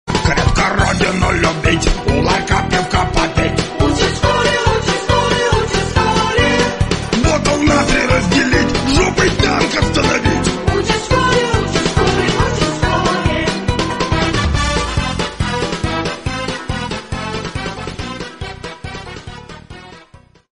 мужской голос
детский голос
цикличные
Забавная вариация детской песенки к 1 сентября